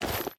equip_chain3.ogg